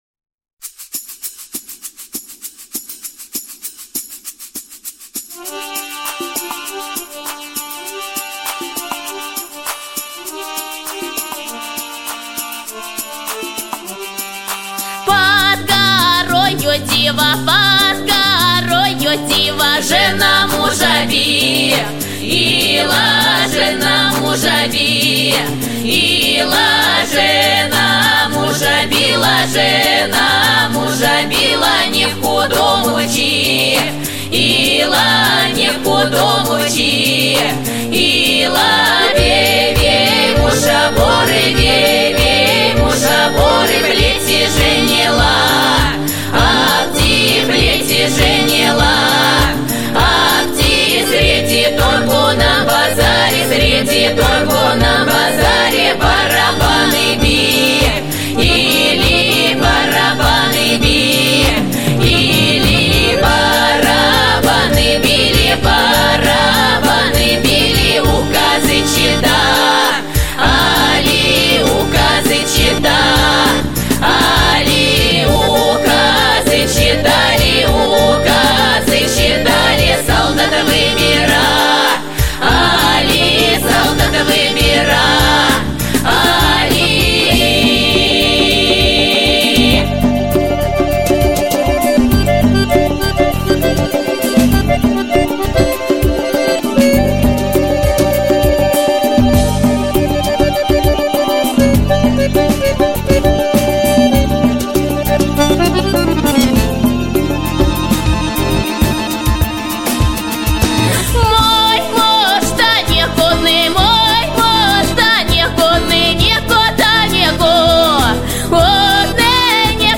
• Жанр: Детские песни
Музыка и слова: народные